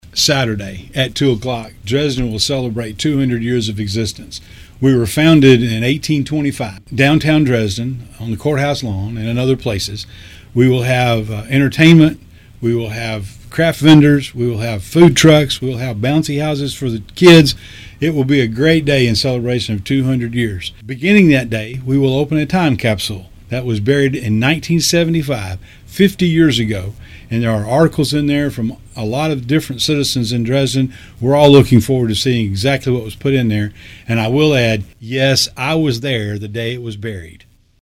Dresden Mayor Mark Maddox tells us Saturday will be a fun day to celebrate the community’s 200 year birth day!